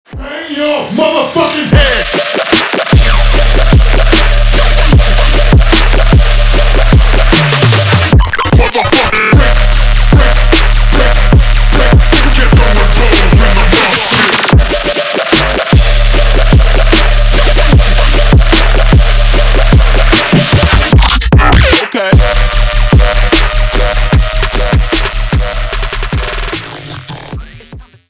Танцевальные
громкие
клубные